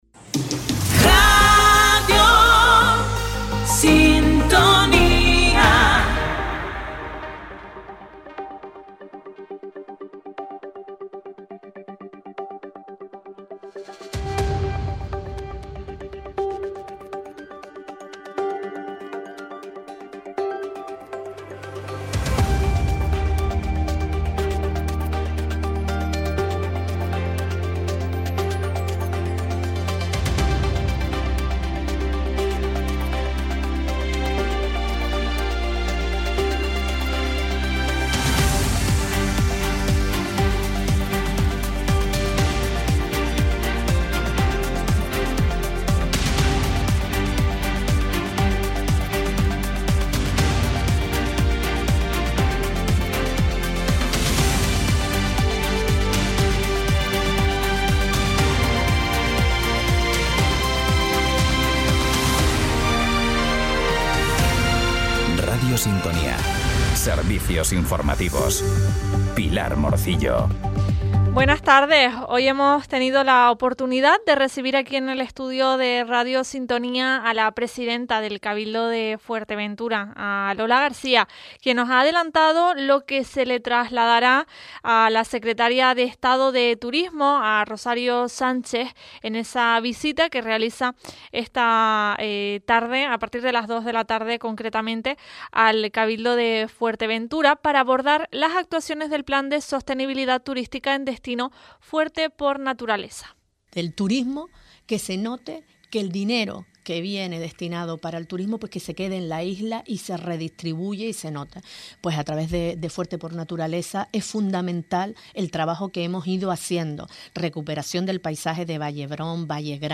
En él te contamos, en directo, las noticias más importantes de la jornada, a partir de las 13:15h.